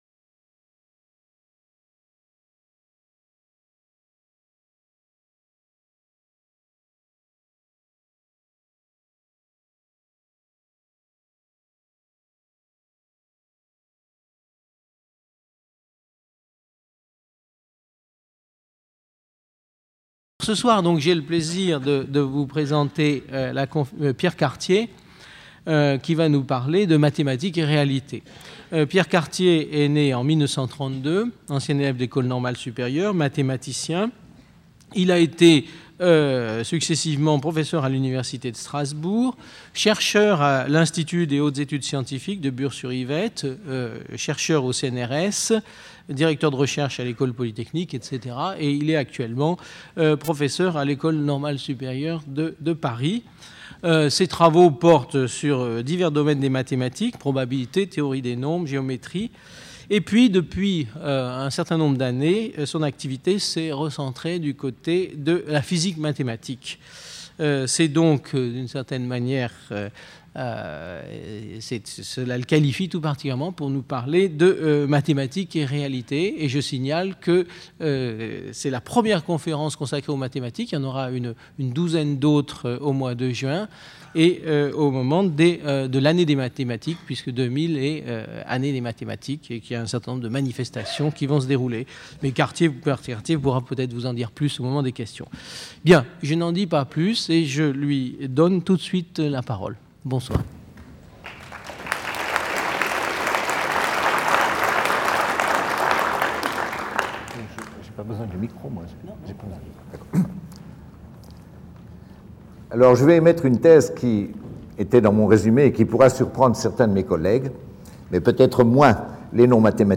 Conférence du 14 janvier 2000 par Pierre Cartier. Nous voulons insister sur le cycle de rétroaction des mathématiques et de la réalité, prise dans son sens social et technologique.